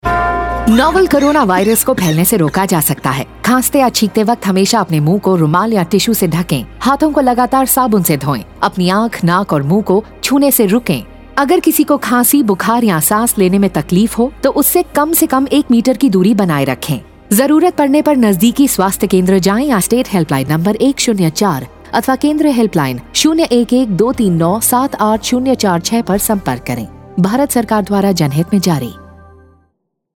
Radio PSA
5143_Cough Radio_Hindi_Jharkhand.mp3